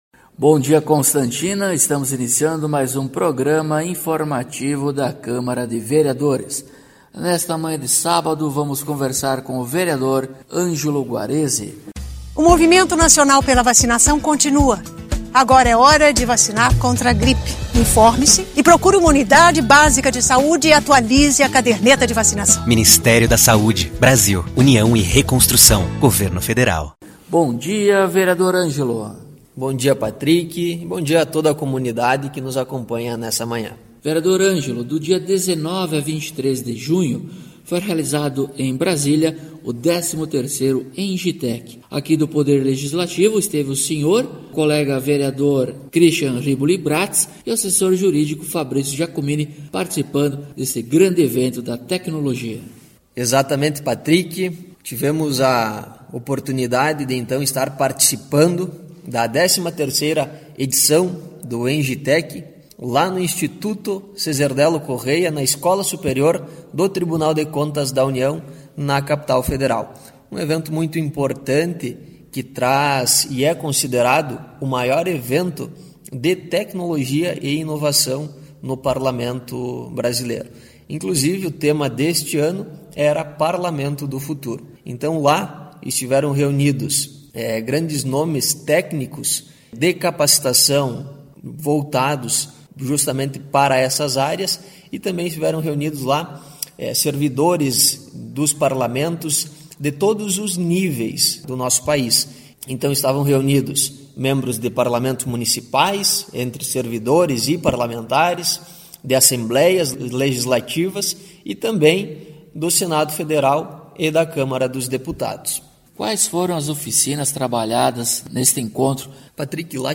Acompanhe o programa informativo da câmara de vereadores de Constantina com o Vereador Ângelo Guarezi.